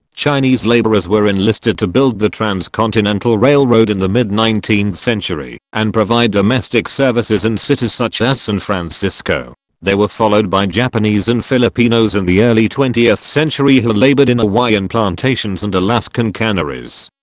Q4. Read Aloud - PTE